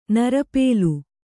♪ narapēlu